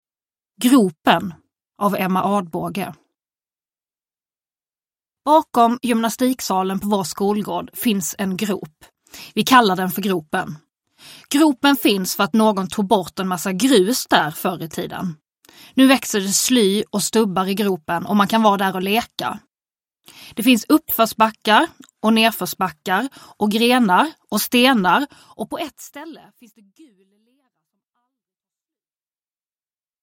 Gropen – Ljudbok – Laddas ner
Uppläsare: Nour El Refai